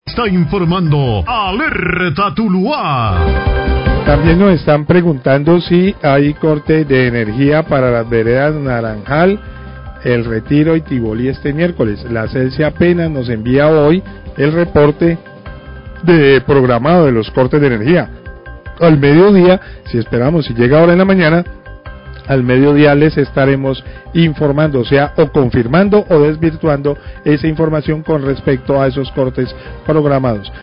Periodista afirma que apenas recibe reporte de cortes programados de Celsia y al mediodía los informará
Radio